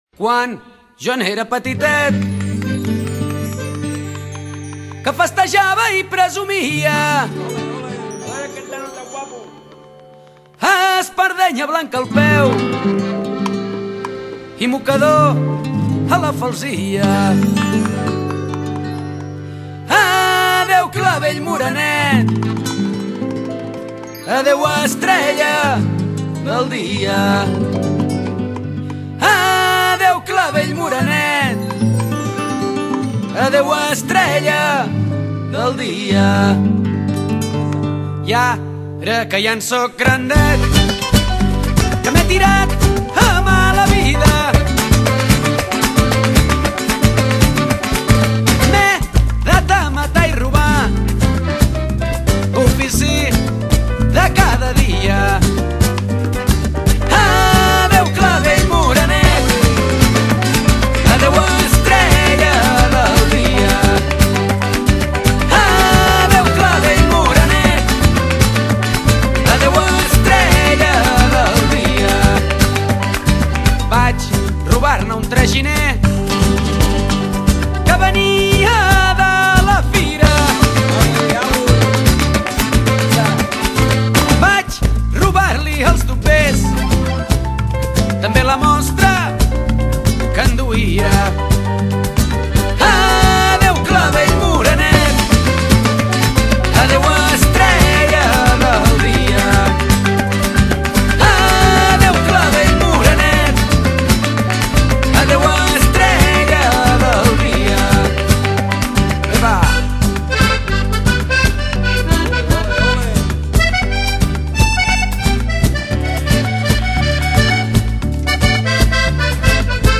una versió molt rumbera
que és un grup català.